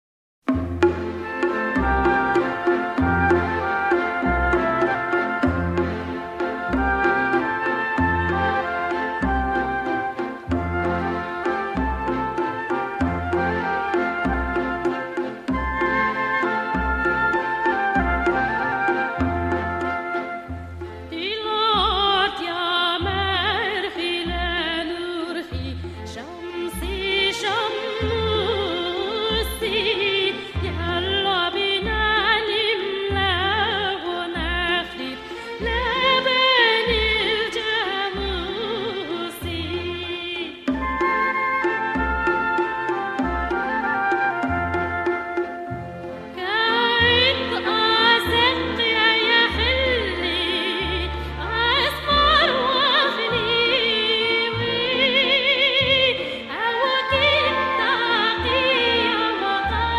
独唱：光辉的太阳